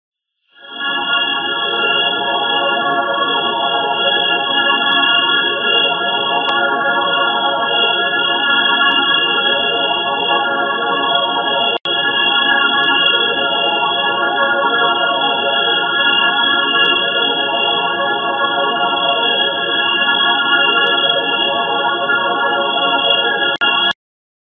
Alien Vocoder Sound Button - Free Download & Play